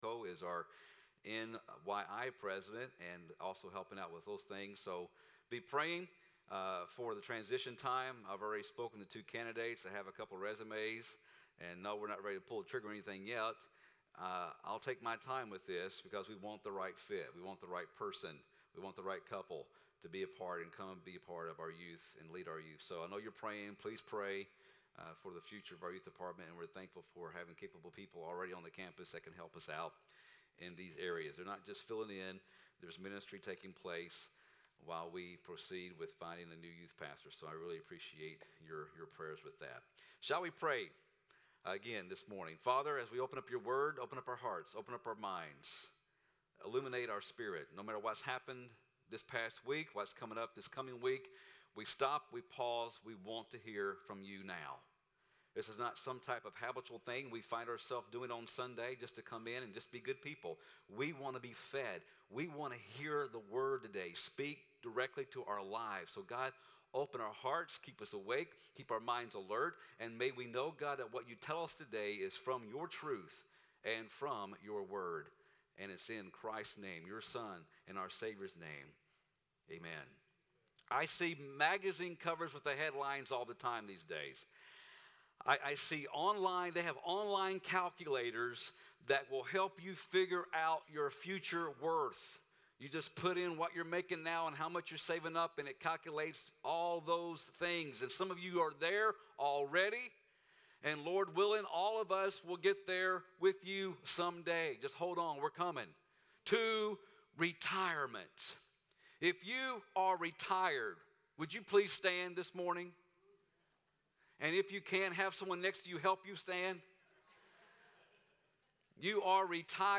Gracepointe Nazarene Church Sermons